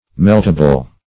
Meltable \Melt"a*ble\, a. Capable of being melted.
meltable.mp3